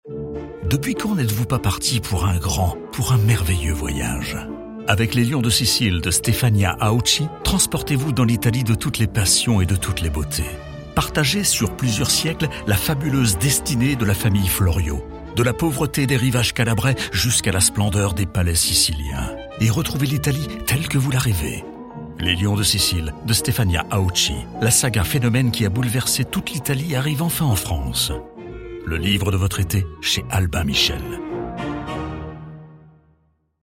Voix off
Bande annonce VSM Radio Caraïbes
À vocation rassurante, explicative ou narrative, ma voix est grave et élégante et colle parfaitement à une tranche d’âge allant de 40 à plus de 70 ans.
- Basse